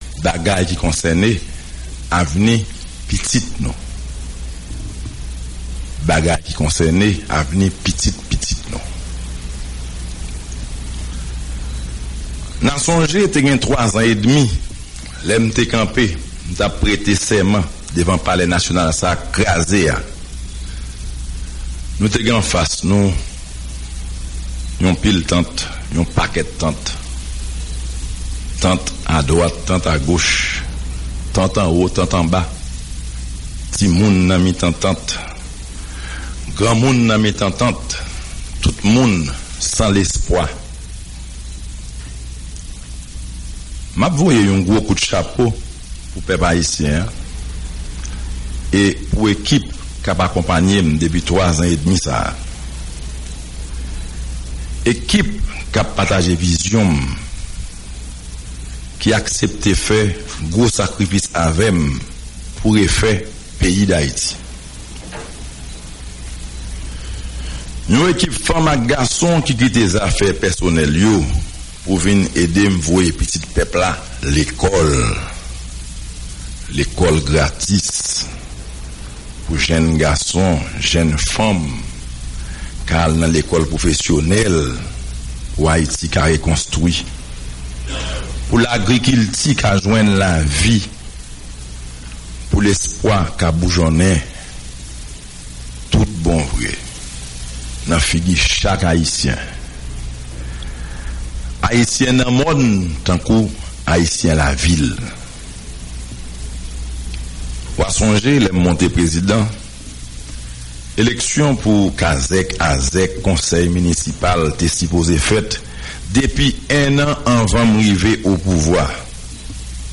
Prezidan ayisyen an detaye opinyon li sou rapò komisyon an nan yon mesaj ki pase nan radyo ak televizyon vandredi 12 desanm 2014 la.